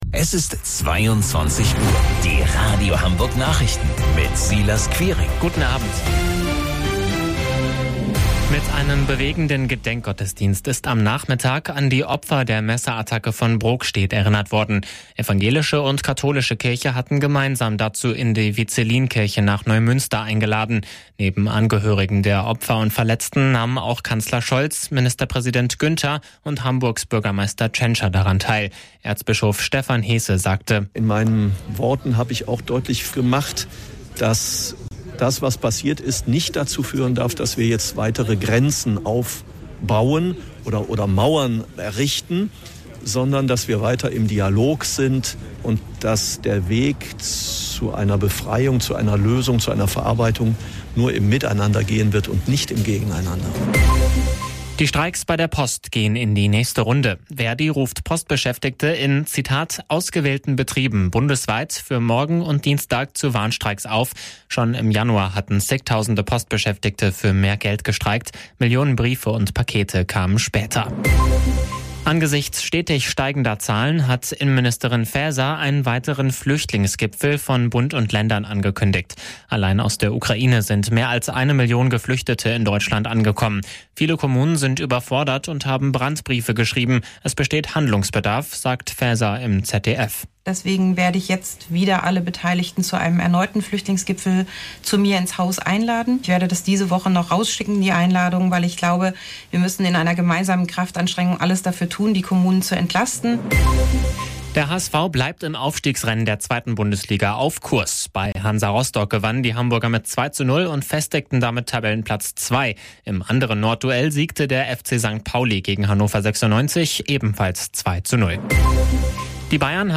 Radio Hamburg Nachrichten vom 13.06.2022 um 16 Uhr - 13.06.2022